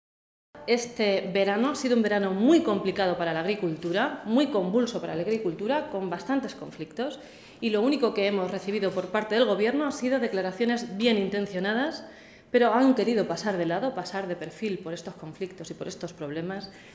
Blanca Fernández, diputada regional del PSOE de C-LM
Cortes de audio de la rueda de prensa